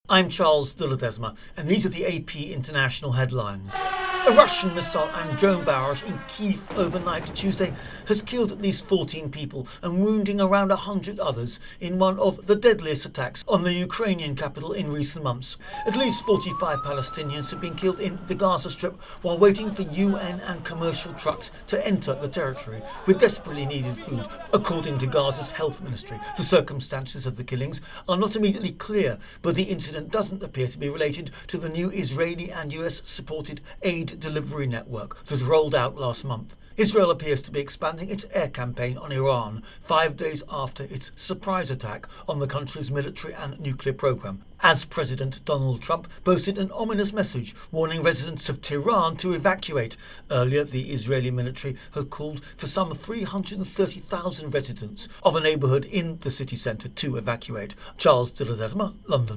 The latest international news